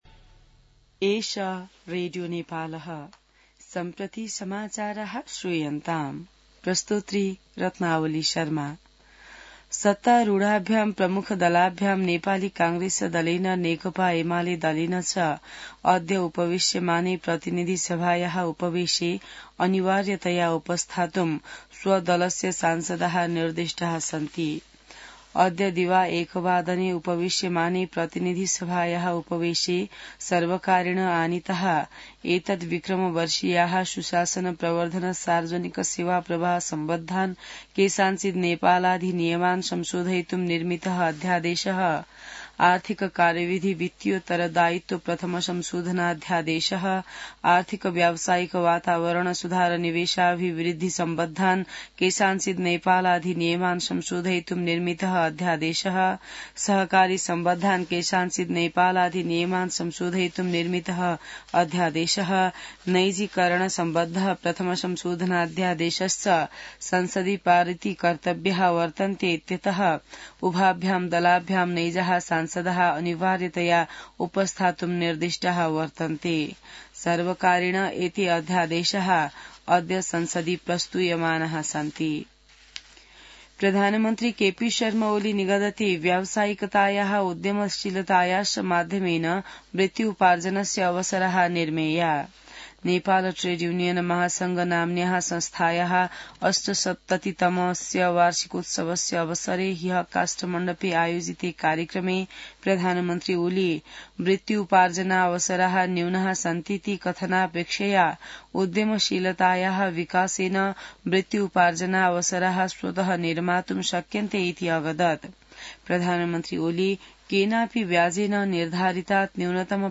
संस्कृत समाचार : २२ फागुन , २०८१